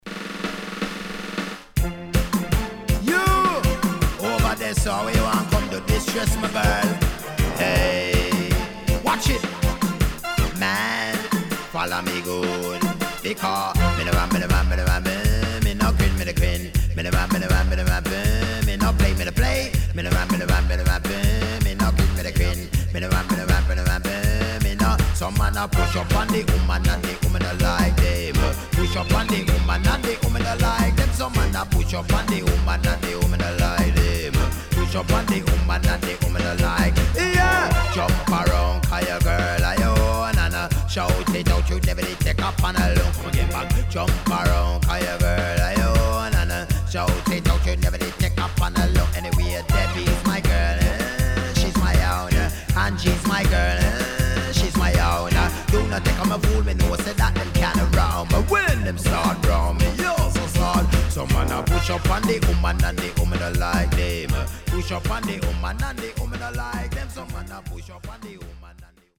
【12inch】
Nice Deejay.W-Side Good
SIDE A:少しチリノイズ入りますが良好です。